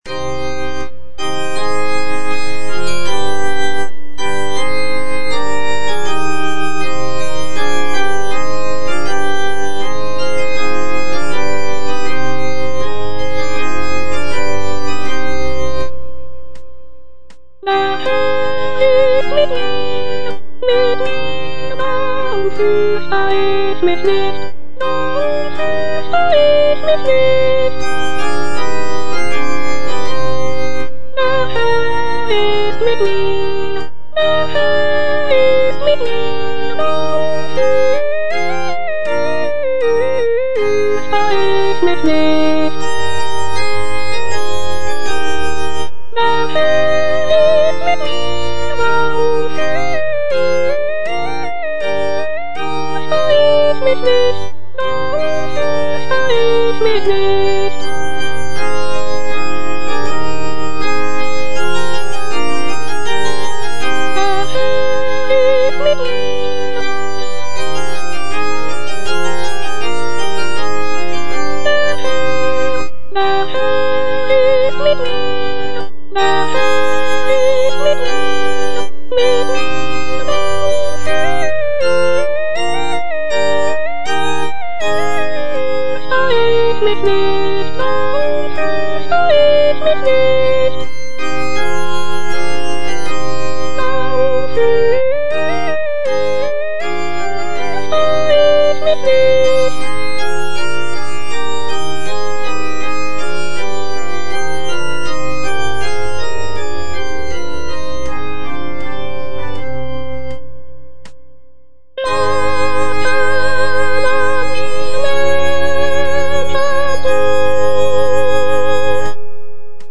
D. BUXTEHUDE - DER HERR IST MIT MIR BUXWV15 Der Herr ist mit mir (bar 1 - 55) - Soprano (Voice with metronome) Ads stop: auto-stop
"Der Herr ist mit mir" (BuxWV 15) is a sacred vocal work composed by Dieterich Buxtehude, a Danish-German composer and organist from the Baroque era. This piece is written for two sopranos, two violins, viola da gamba, and continuo.